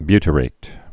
(bytə-rāt)